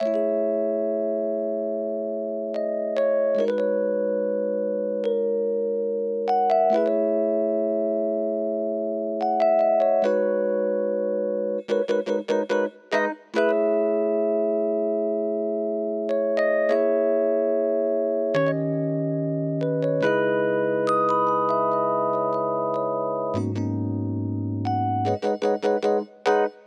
05 rhodes C.wav